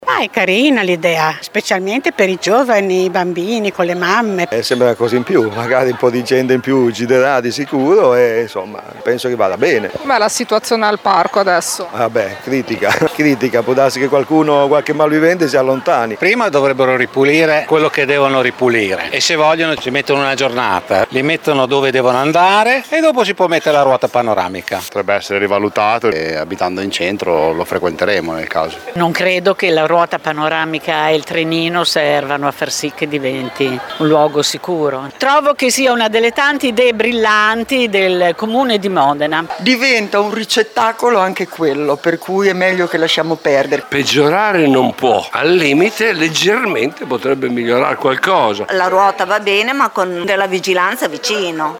VOX-RUOTA-NOVI-SAD.mp3